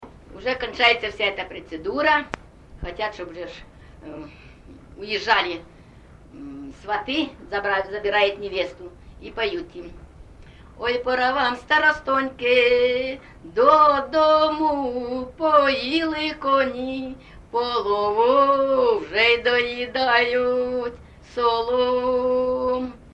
ЖанрВесільні
Місце записум. Маріуполь, Донецька обл., Україна, Північне Причорноморʼя